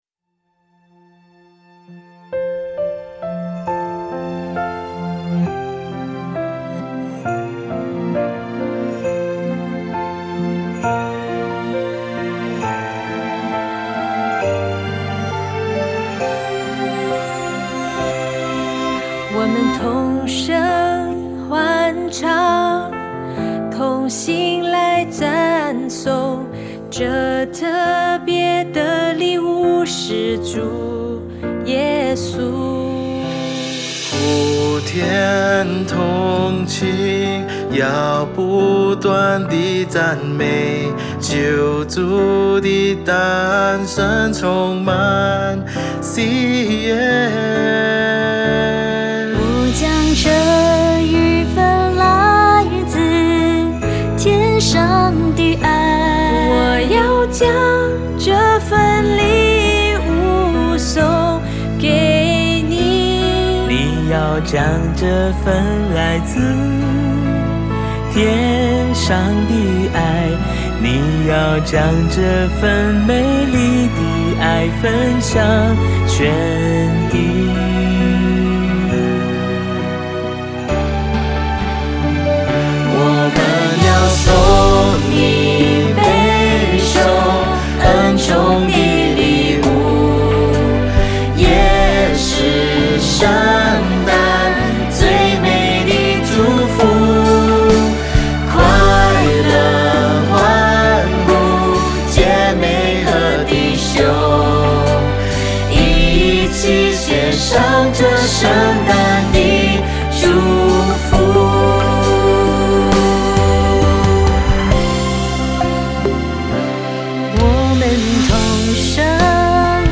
这首作品原是欢乐曲风且比较快的节奏，但后来发现把曲风换成温馨的节奏反而更贴近人心。